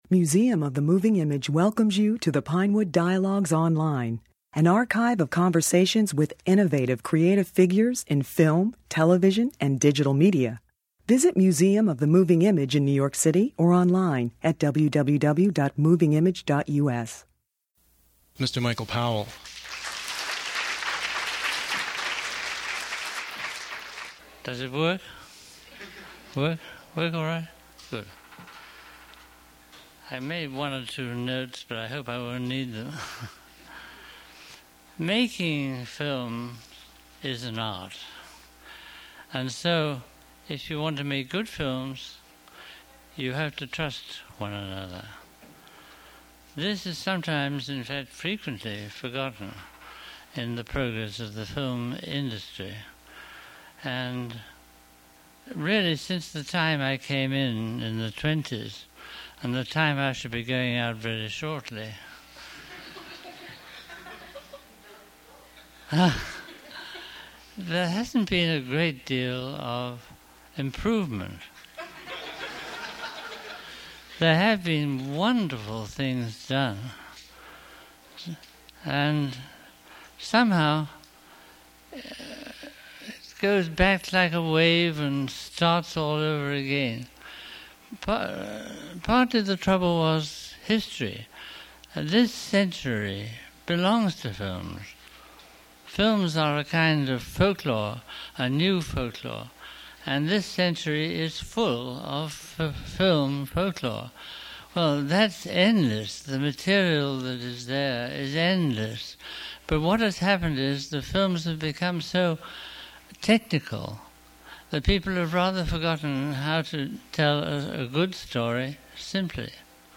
In August 1989, Powell made one of his last public appearances at the Museum of the Moving Image. He presented Peeping Tom in the film series Hollywood Beyond Sunset, and spoke passionately about his life and career